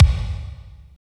32.05 KICK.wav